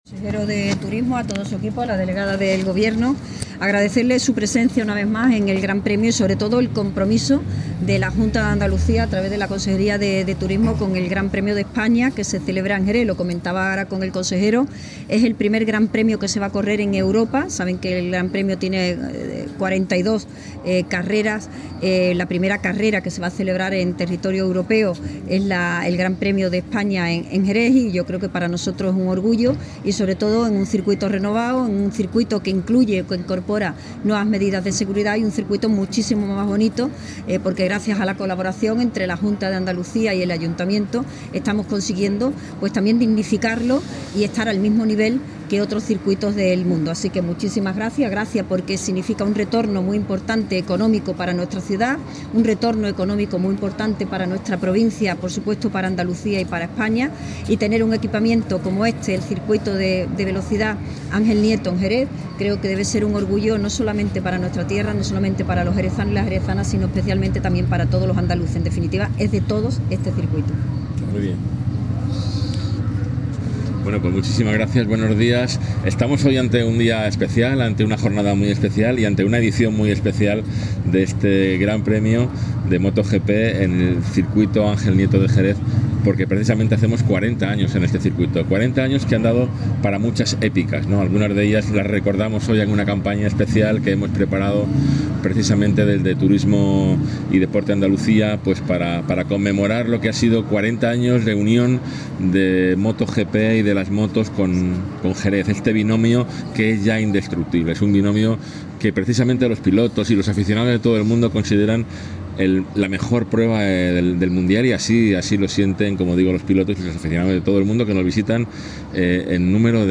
Así lo ha explicado en la atención a medios de comunicación antes de asistir a la jornada del sábado del Gran Premio en una edición muy especial dado que el circuito Ángel Nieto cumple 40 años.
Audio del Consejero de Turismo y Andalucía Exterior (MP3)